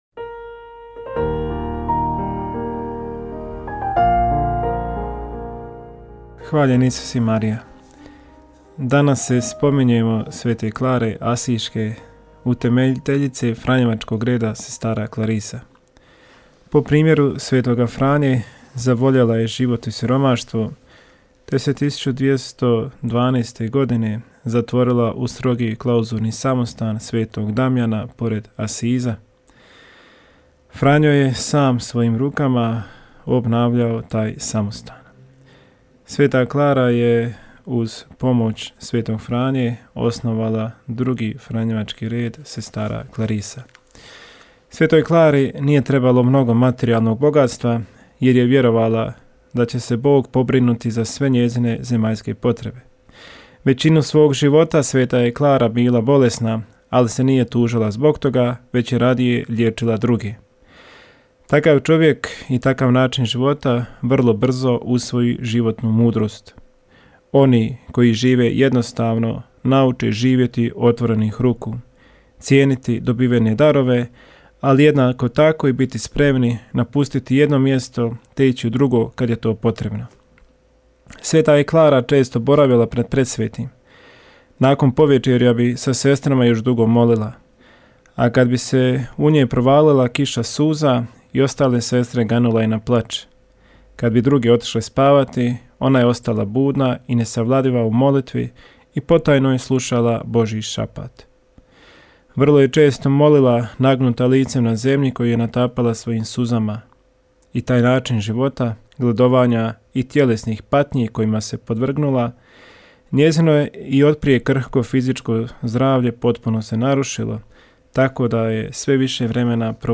Kratku emisiju ‘Duhovni poticaj – Živo vrelo’ slušatelji Radiopostaje Mir Međugorje mogu čuti od ponedjeljka do subote u 3 sata i u 7:10. Emisije priređuju svećenici i časne sestre u tjednim ciklusima.